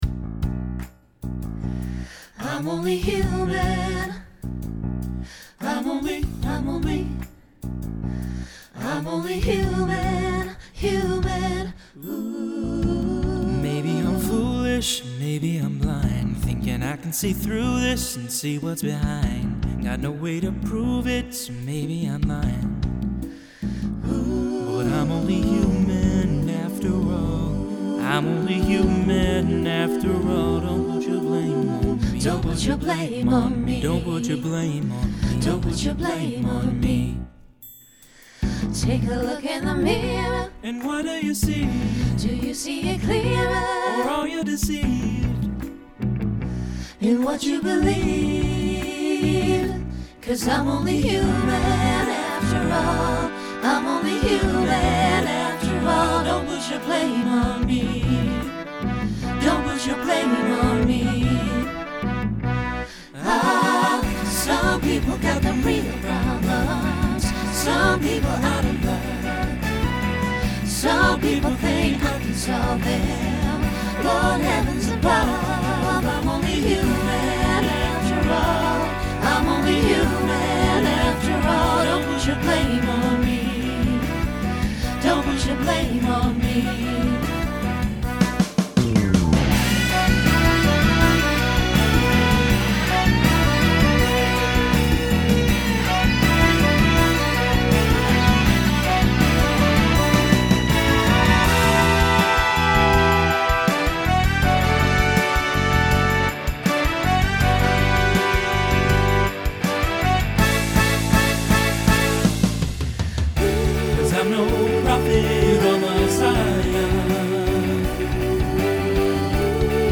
Genre Rock Instrumental combo
Mid-tempo , Opener Voicing SATB